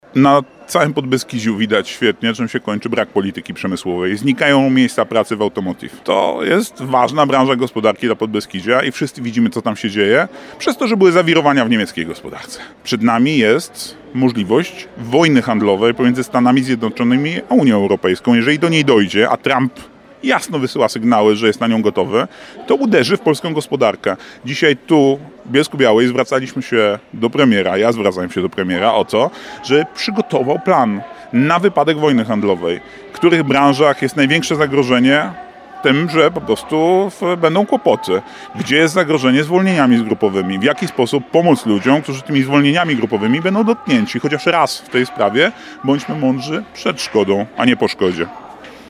To są lokalne miejsca pracy, dlatego warto posłuchać tych ludzi – mówił w rozmowie z naszym reporterem.